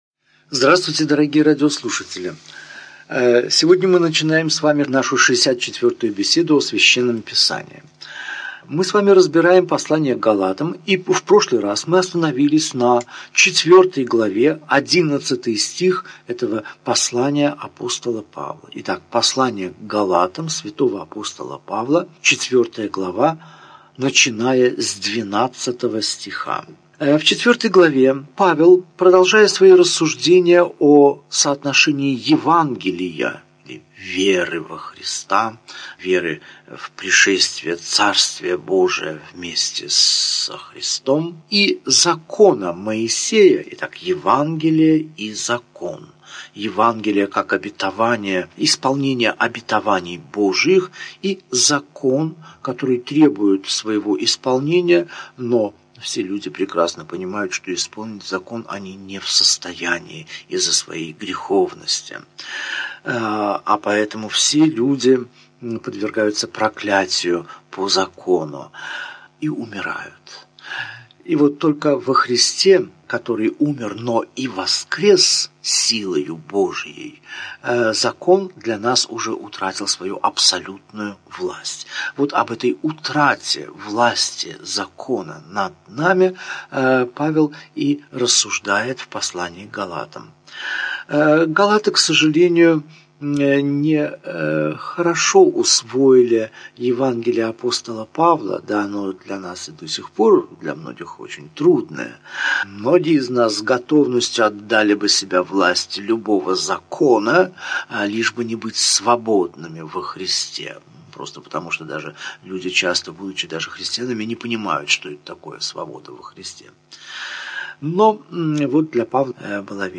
Аудиокнига Беседа 64. Послание к Галатам. Глава 3, стихи 12 – 31 | Библиотека аудиокниг